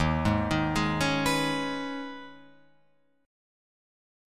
D#7sus4#5 chord